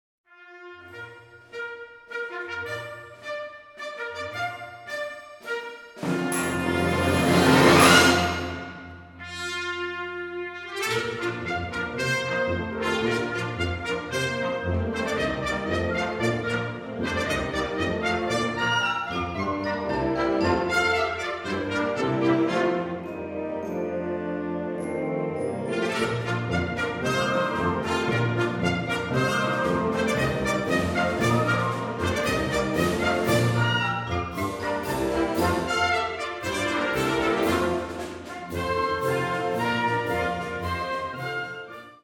Categorie Harmonie/Fanfare/Brass-orkest
Subcategorie Programmatische muziek
Bezetting Ha (harmonieorkest)